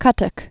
(kŭtək)